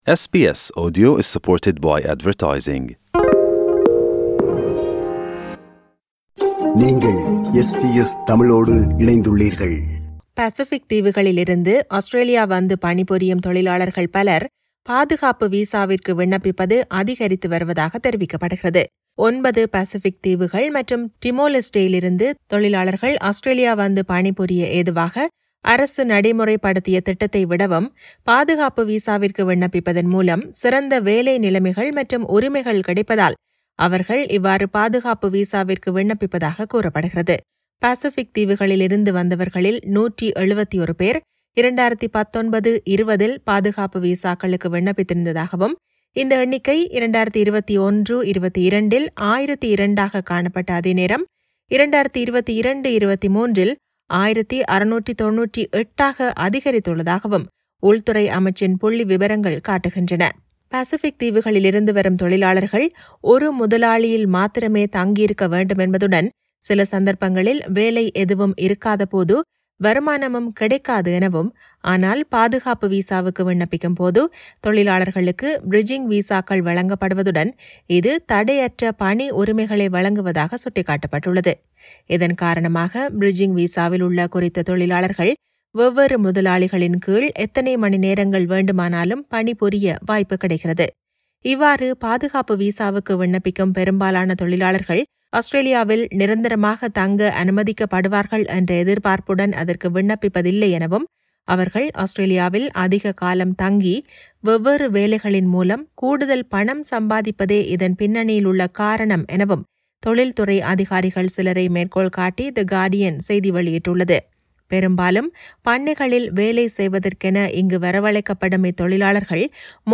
செய்தி விவரணத்தை